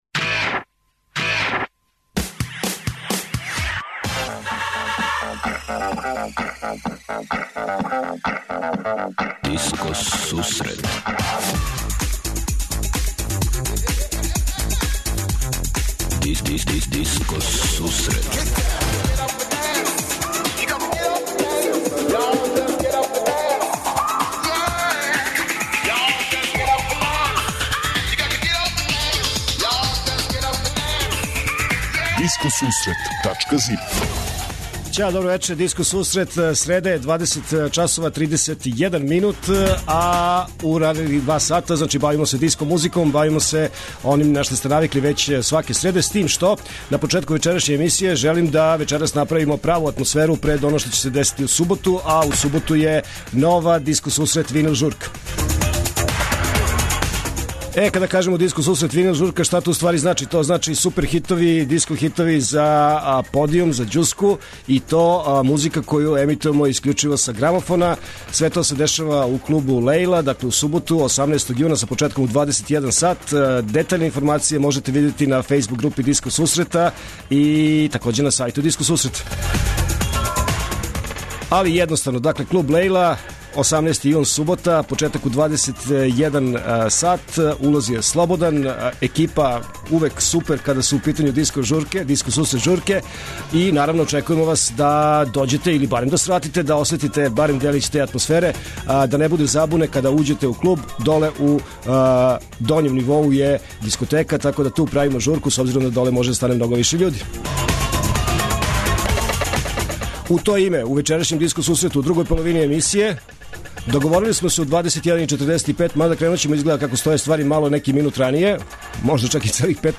Од 20:30 Диско Сусрет Топ 40 - Топ листа 40 највећих светских диско хитова.
21:30 Винил Зона - Слушаоци, пријатељи и уредници Диско Сусрета за вас пуштају музику са грамофонских плоча.
преузми : 54.02 MB Discoteca+ Autor: Београд 202 Discoteca+ је емисија посвећена најновијој и оригиналној диско музици у широком смислу, укључујући све стилске утицаје других музичких праваца - фанк, соул, РнБ, итало-диско, денс, поп.